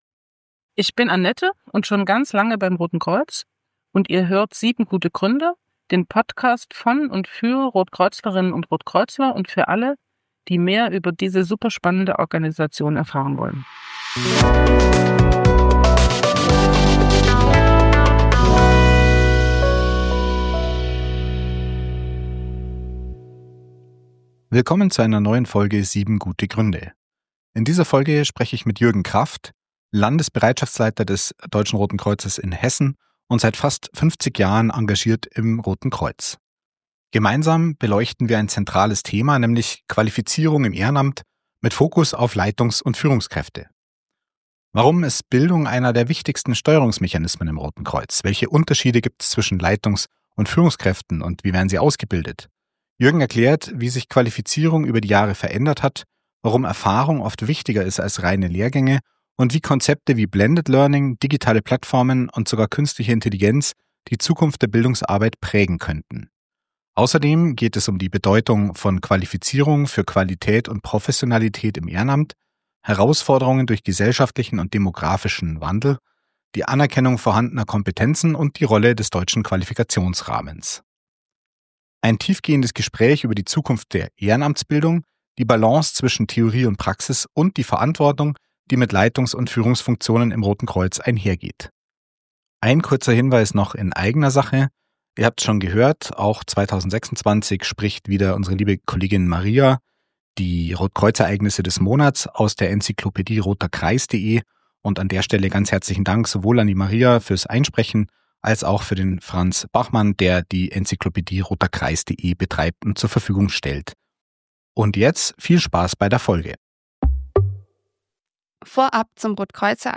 Ein tiefgehendes Gespräch über die Zukunft der Ehrenamtsbildung, die Balance zwischen Theorie und Praxis und die Verantwortung, die mit Leitungs- und Führungsfunktionen im Roten Kreuz einhergeht.